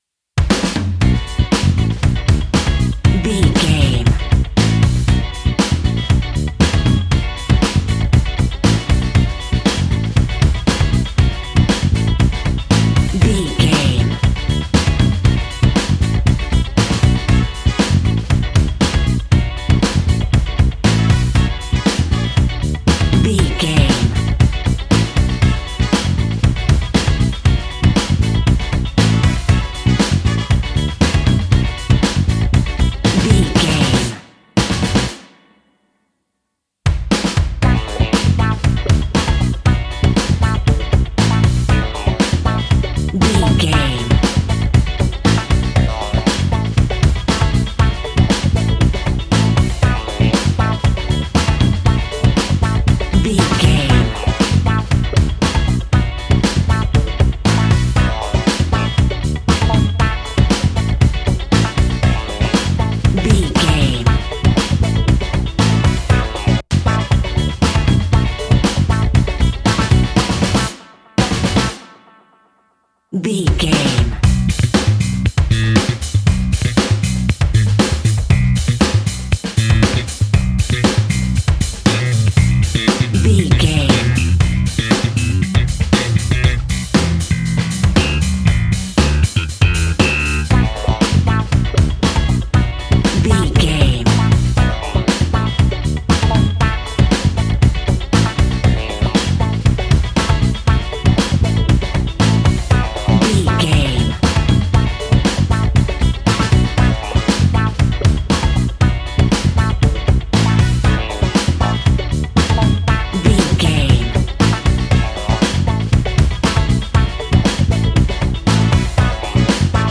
Aeolian/Minor
G♭
groovy
lively
electric guitar
electric organ
drums
bass guitar
saxophone
percussion